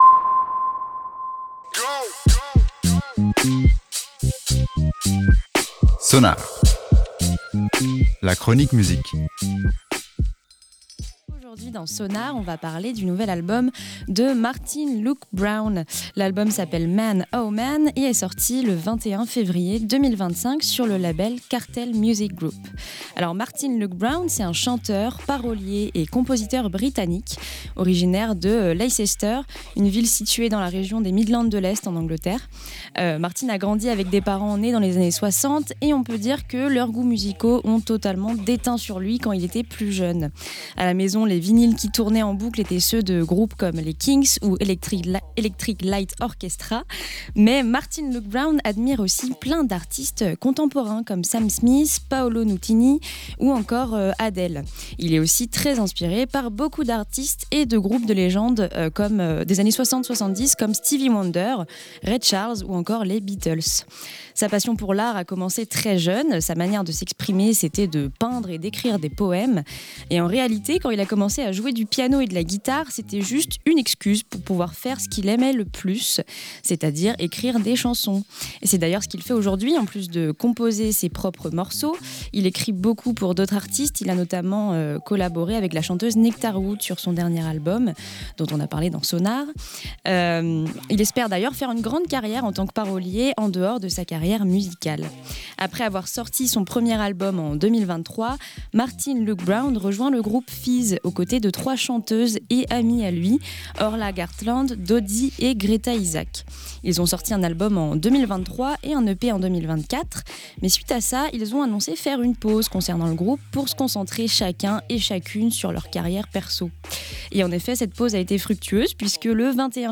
Deux ans après son premier album, le chanteur britannique revient avec un nouvel album hyper réconfortant.
Chaque morceau est une introspection profonde de la part du chanteur. Le tout avec des sonorités douces et parfaitement maîtrisées.